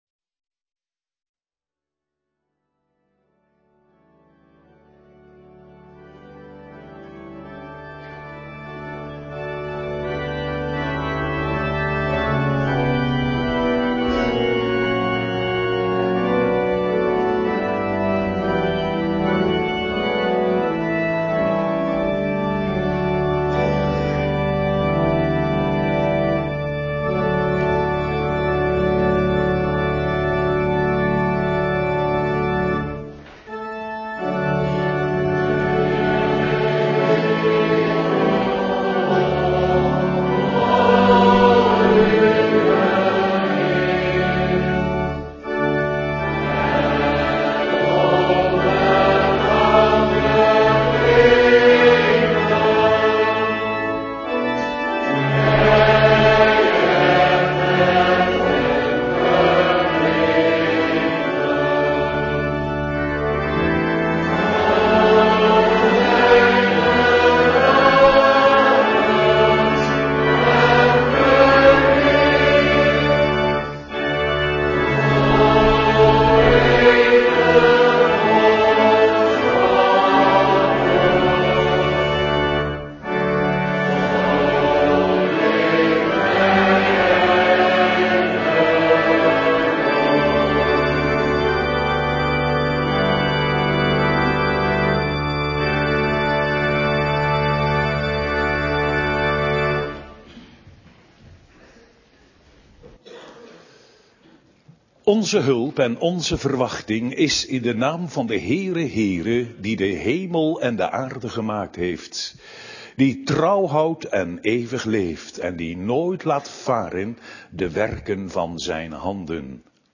Doopdienst | CGK Rijnsburg